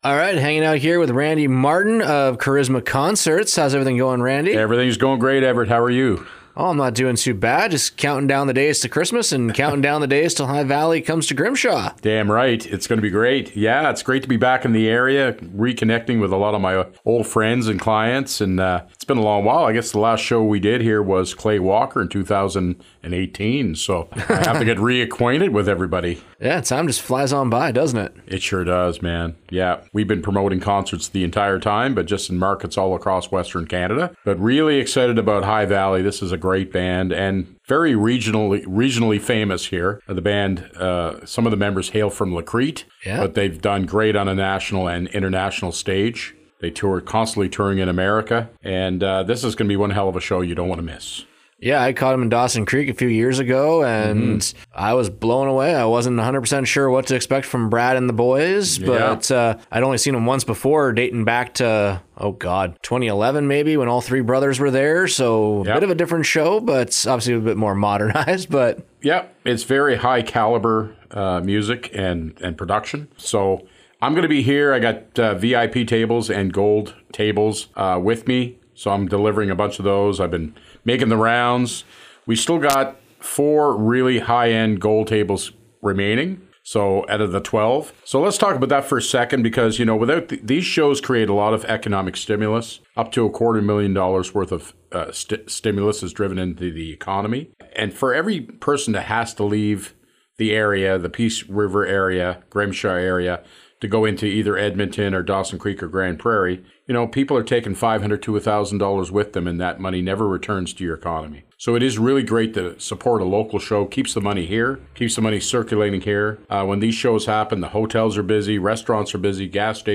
Here is the full conversation: